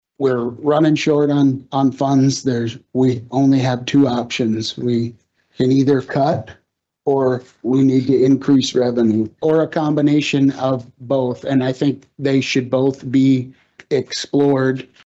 Commissioner Dan Klimisch says he believes the county has two options, cut or increase revenue.
The county commission announced the committee at Tuesday’s commission meeting.